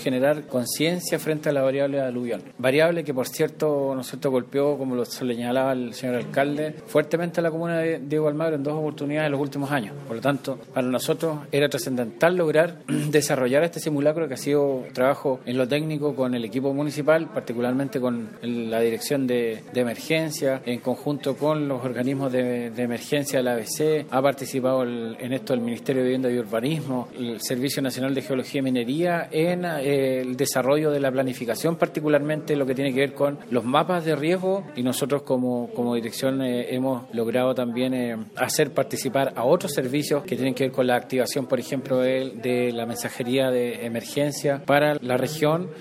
Javier Sáez, director de la ONEMI, se refirió al objetivo de esta actividad, indicando que
DIRECTOR-ONEMI-CUÑA-1.mp3